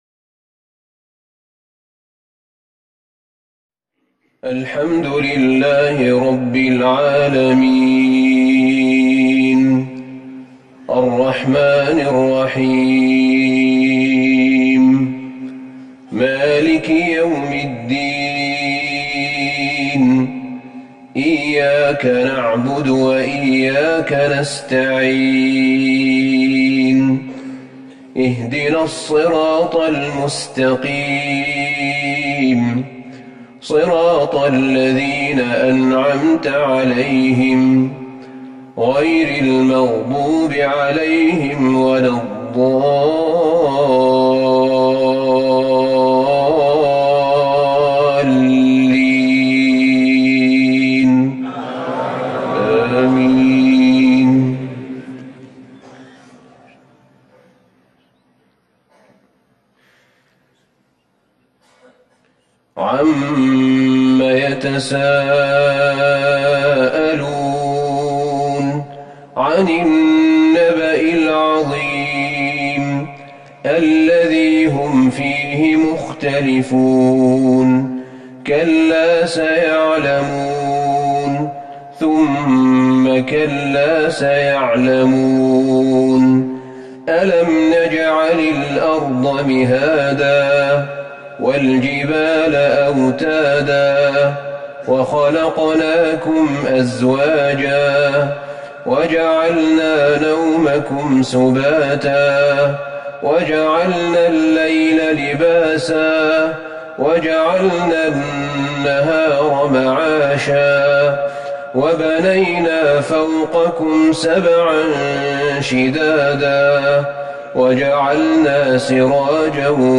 صلاة العشاء ١٦ جمادي الاولى ١٤٤١هـ سورة النبأ والماعون Evening prayer 4-1-2020 from Surat Al-Naba and Al-Ma`un > 1441 🕌 > الفروض - تلاوات الحرمين